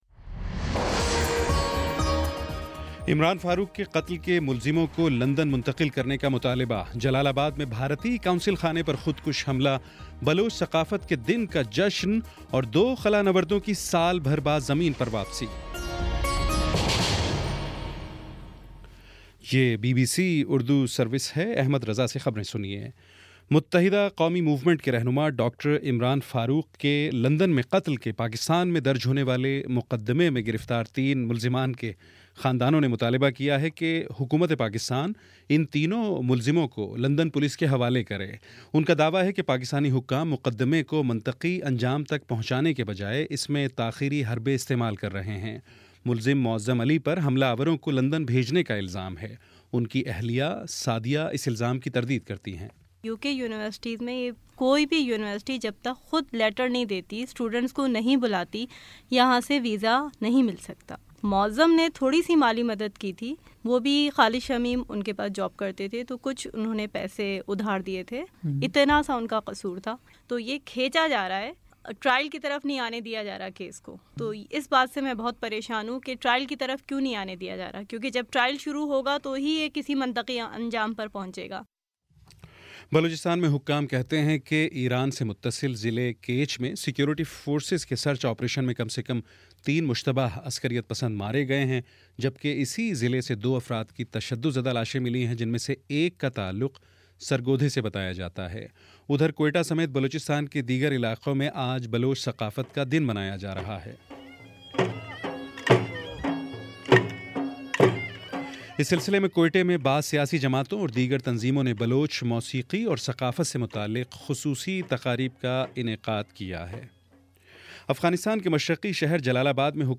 مارچ 02 : شام چھ بجے کا نیوز بُلیٹن